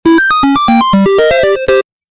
Download Free Video Game sound effect for free.